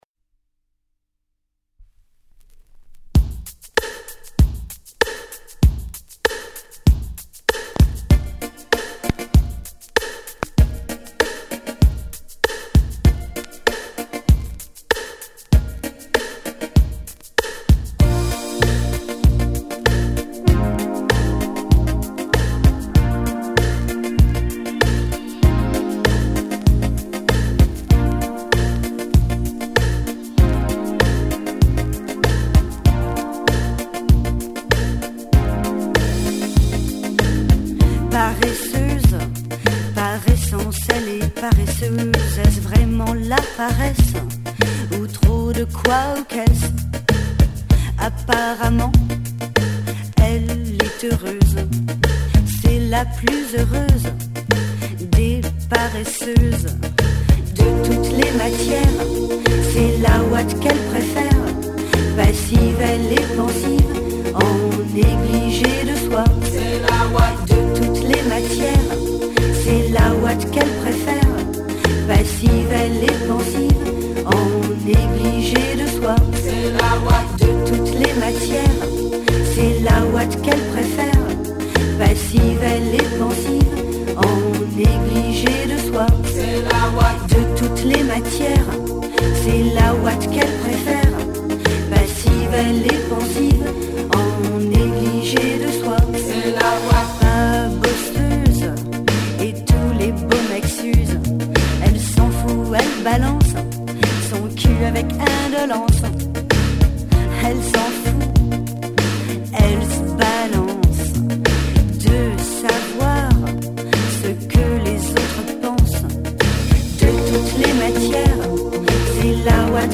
Musica a 360°, viva, legata e slegata dagli accadimenti.